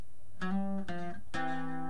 Las letras entre paréntesis son los requintos que están al final!!!